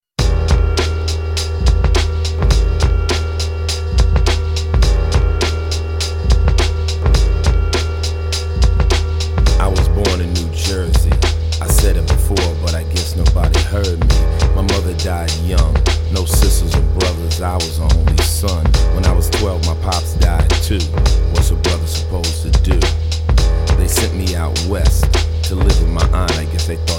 • Качество: 128, Stereo
рэп
ганста рэп